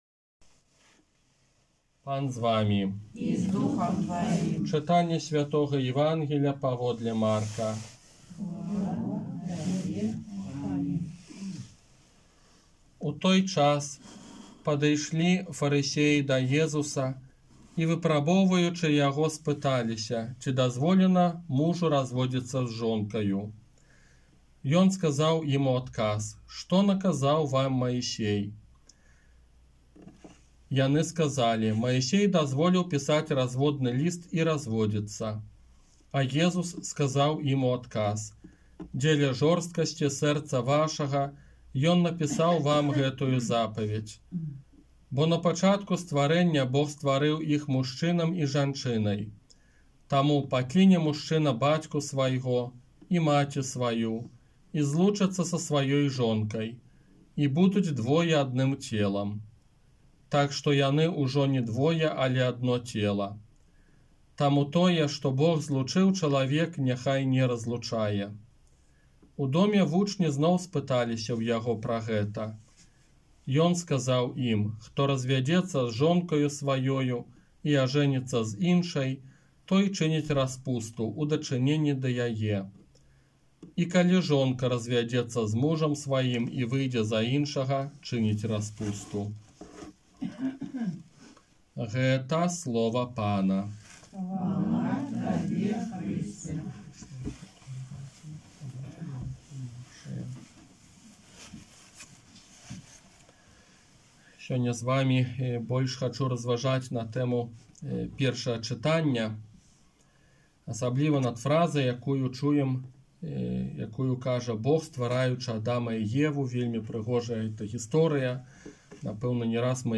ОРША - ПАРАФІЯ СВЯТОГА ЯЗЭПА
Казанне на дваццаць сёмую звычайную нядзелю 3 кастрычніка 2021 года